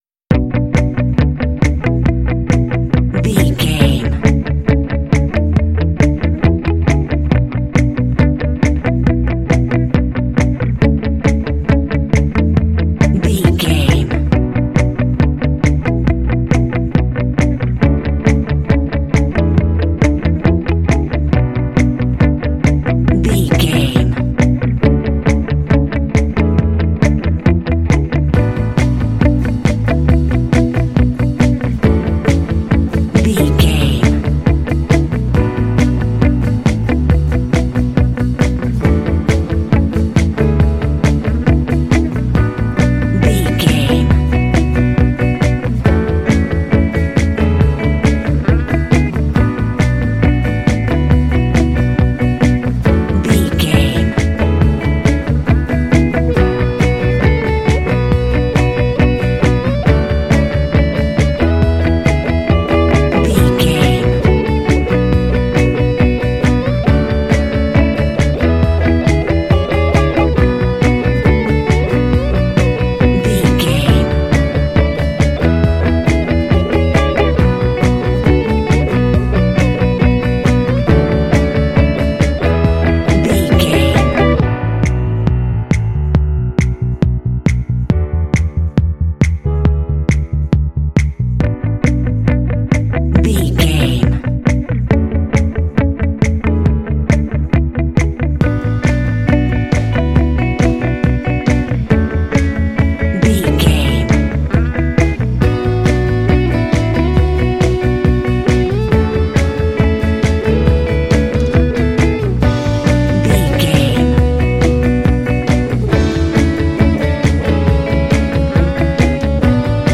Uplifting
Aeolian/Minor
energetic
bass guitar
electric guitar
drums
classic rock